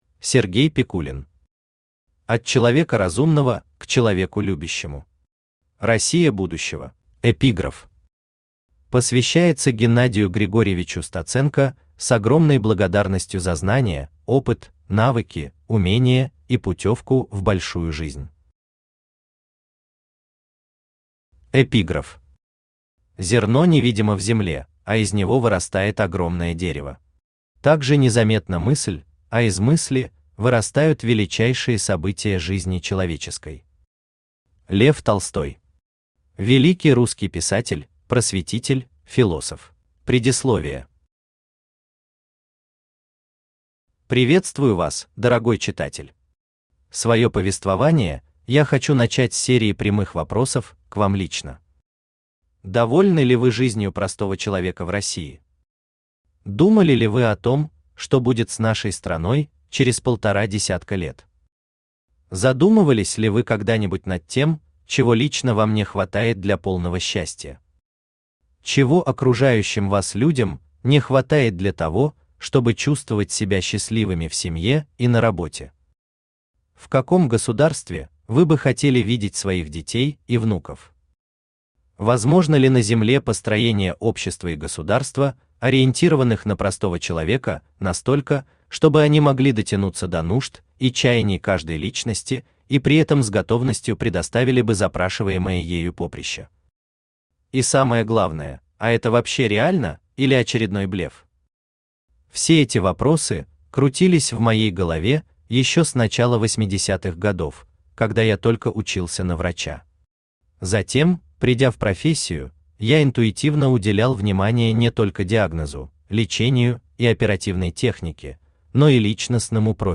Аудиокнига От Человека разумного к Человеку любящему. Россия будущего | Библиотека аудиокниг
Россия будущего Автор Сергей Пикулин Читает аудиокнигу Авточтец ЛитРес.